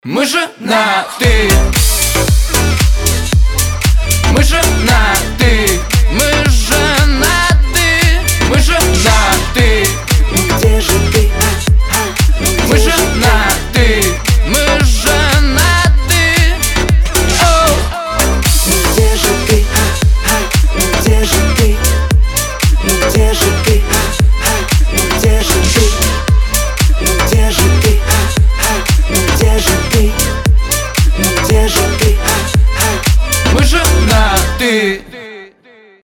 • Качество: 320, Stereo
громкие
заводные
Dance Pop
house